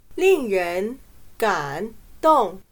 令人感动/lìng rén gǎndòng/Se refiere a la expresión de estar conmovido en la cara, describiendo estar conmovido por palabras y acciones.